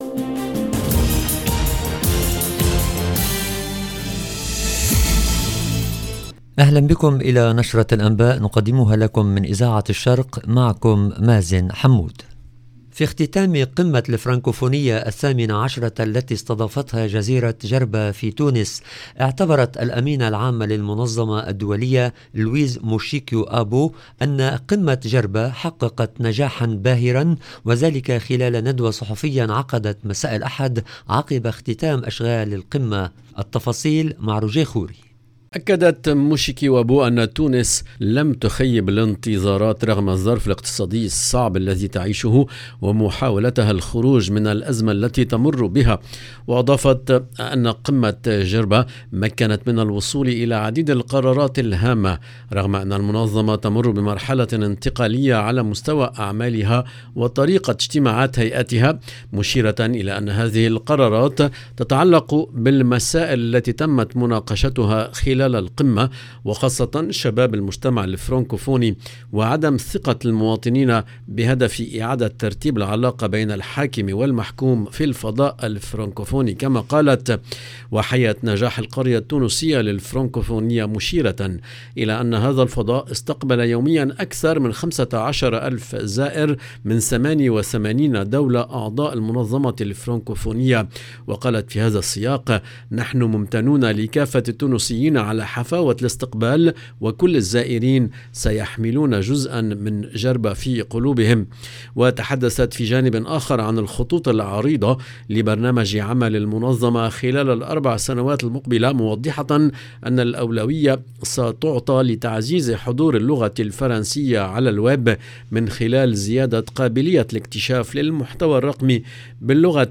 LE JOURNAL DU SOIR EN LANGUE ARABE DU 21/11/22